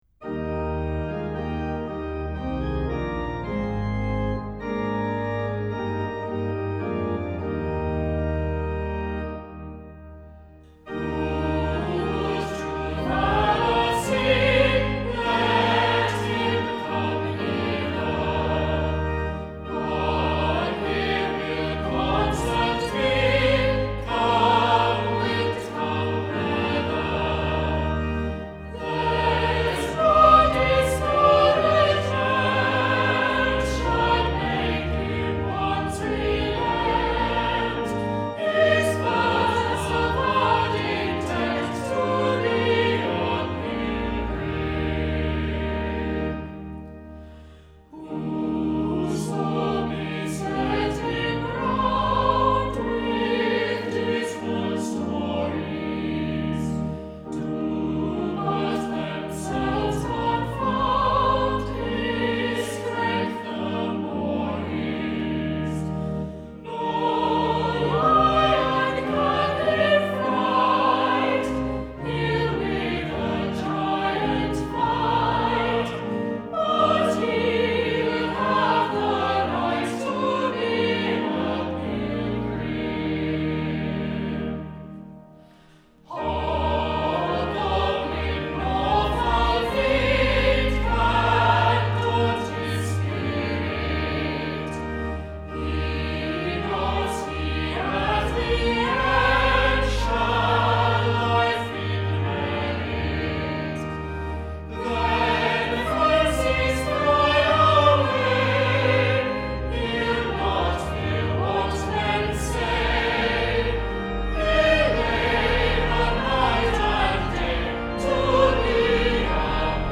Hymns and songs were recorded remotely by the Choral Scholars of St Martin-in-the-Fields in their homes, and edited together.
Opening Hymn Who would true valour see